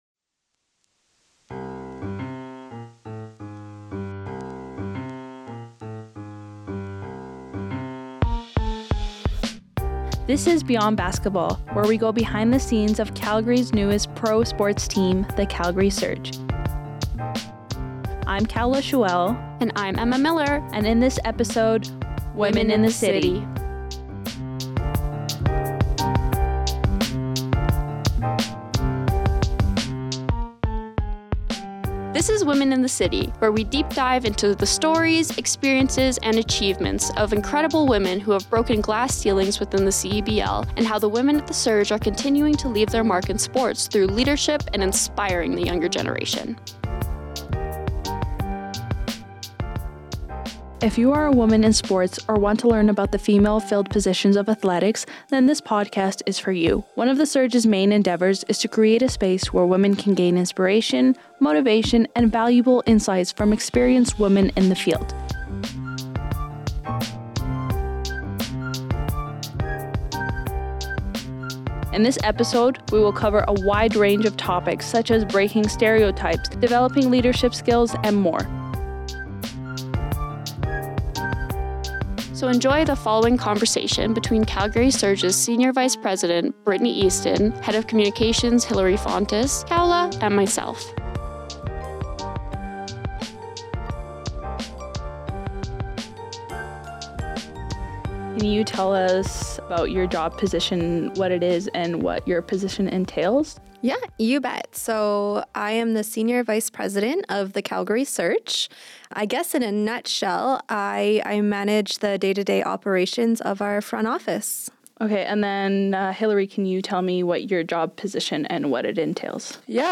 In this episode, we sit down and have a chat, woman to woman, about the obstacles and advantages of being female in a predominantly male space.&nbsp